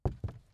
WoodFootsteps
ES_Walk Wood Creaks 5.wav